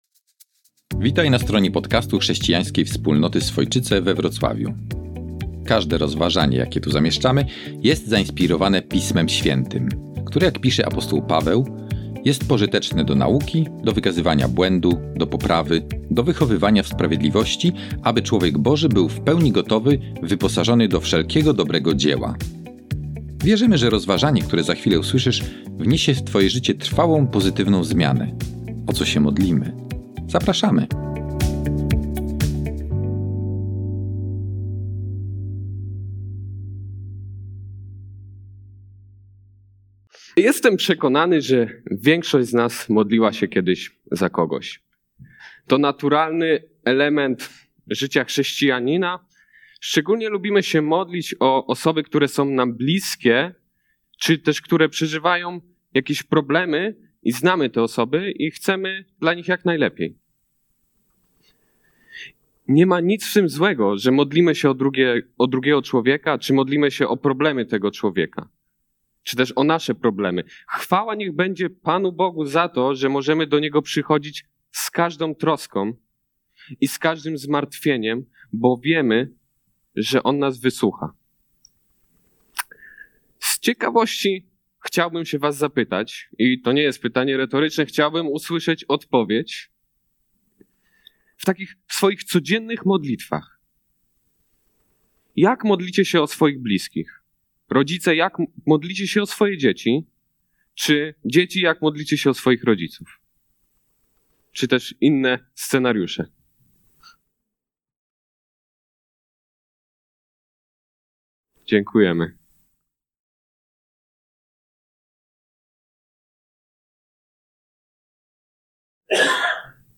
Nauczanie z dnia 24 listopada 2024